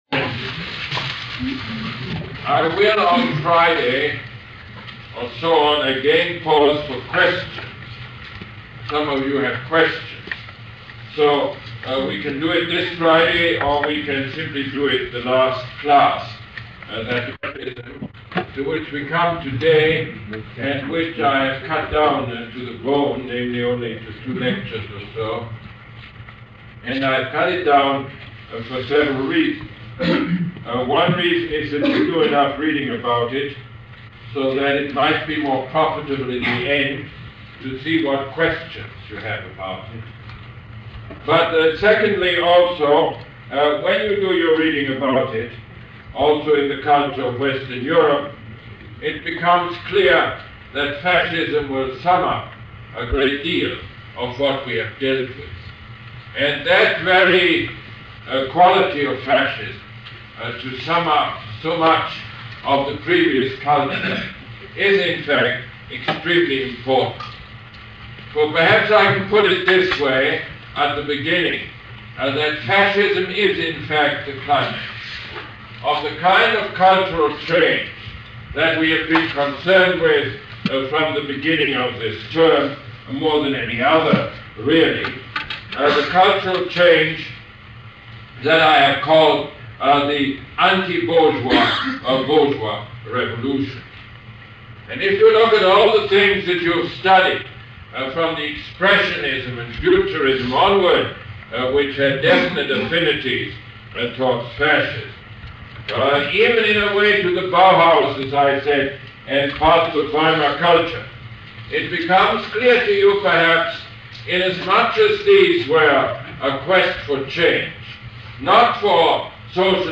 Lecture #27 - December 5, 1979